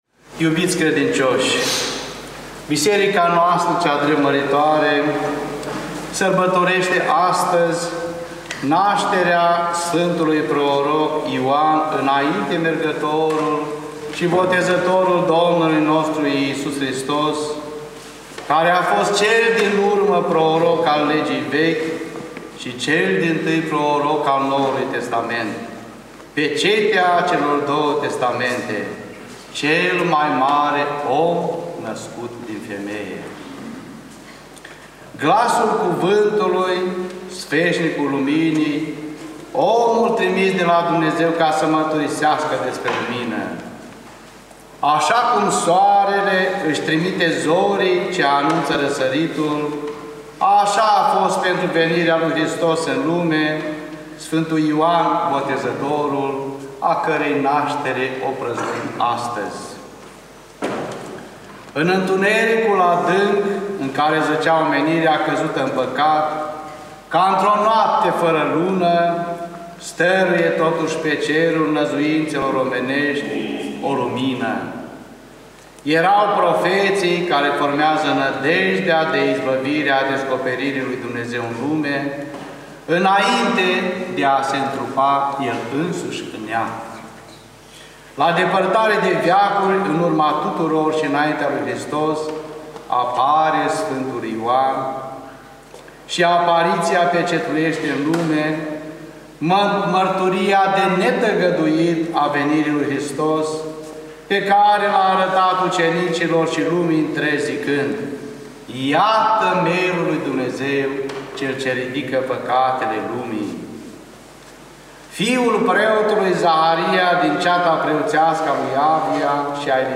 Arhivă de predici la Nașterea Sf. Ioan Botezătorul / ortodoxradio